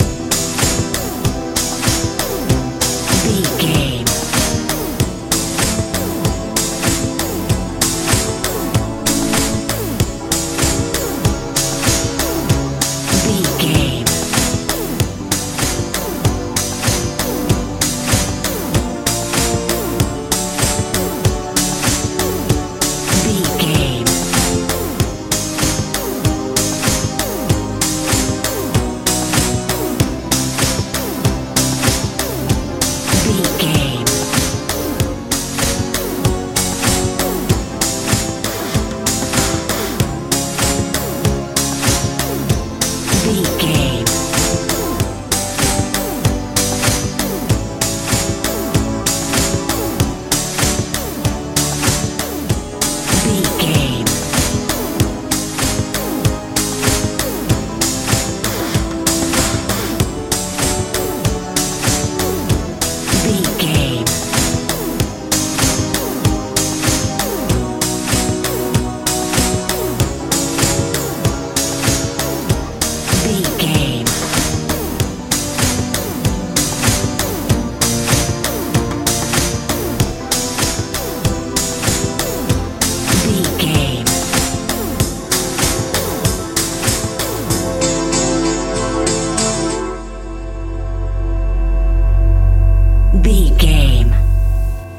pop dance feel
Ionian/Major
futuristic
strange
synthesiser
bass guitar
drums
80s
90s
suspense
tension
bright